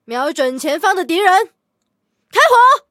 LT-35开火语音2.OGG